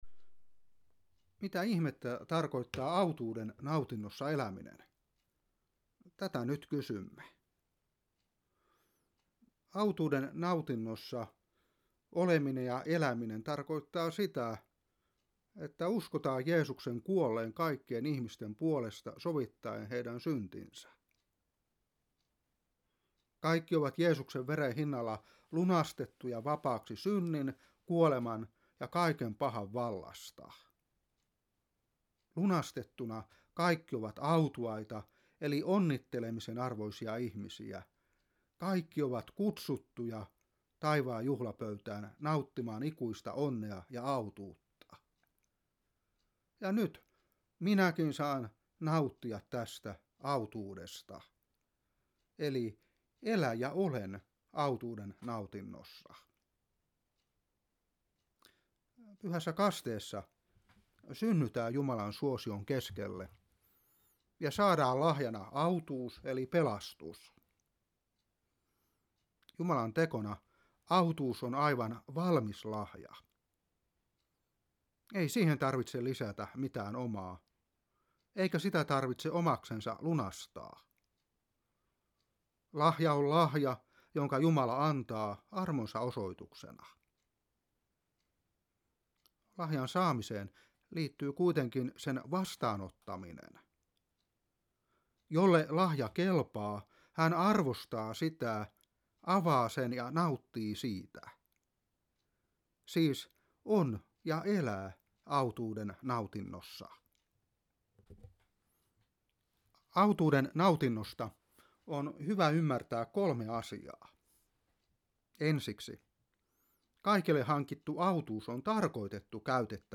Opetuspuhe 2018-4. 2.Kor.5:20; 6:1. Joh.20:26-27. Mark.16:15-16. Room.10:10.